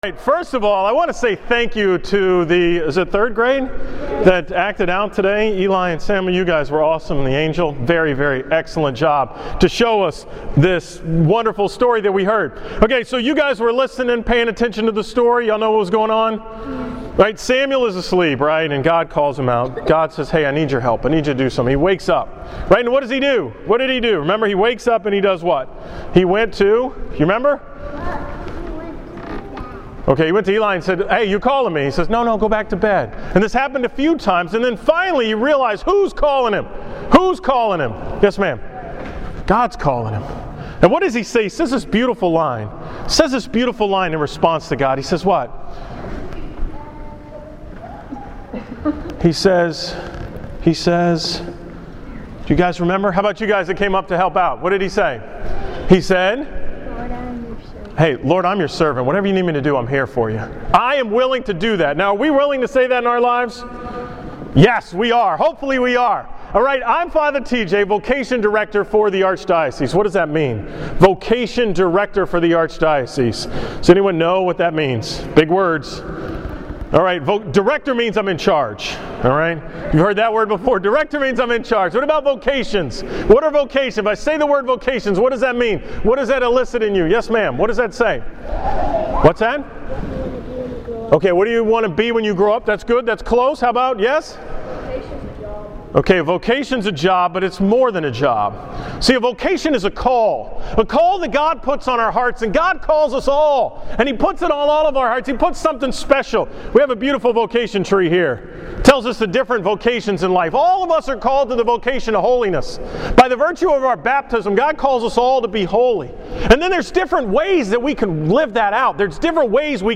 From the school Mass at St. Michael's on November 5, 2014